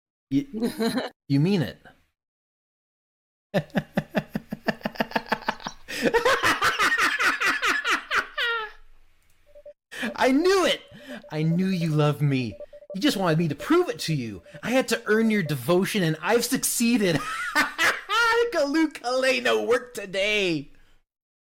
I Love His Evil Laughter Sound Effects Free Download